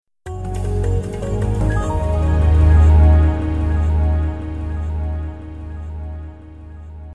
Sonido con Reverberación
Con Reverberacion.mp3